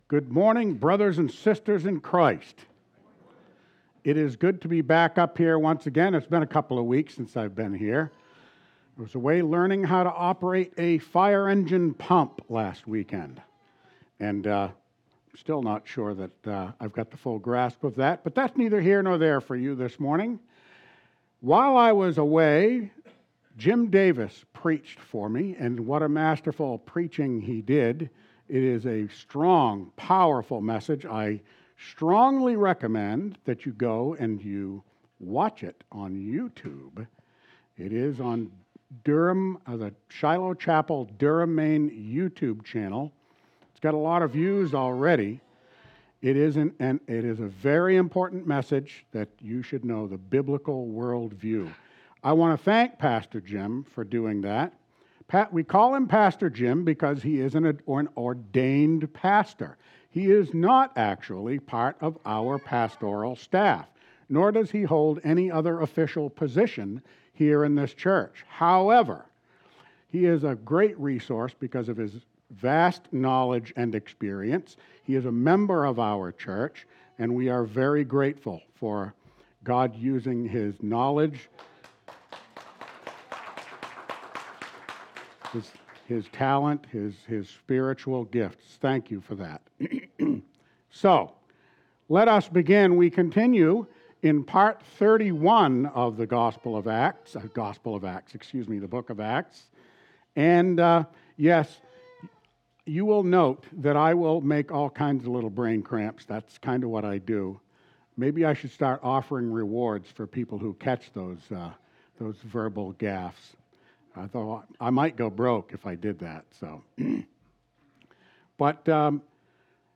Sunday, October 6, 2024, Worship Service: Acts Part 31 “The Pisidian Reaction!”